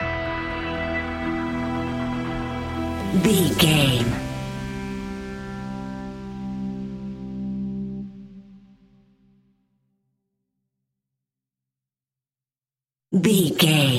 High Action Suspense Scary Music Stinger.
Aeolian/Minor
dramatic
intense
synthesiser
drums
strings
electric guitar
suspenseful
creepy
horror music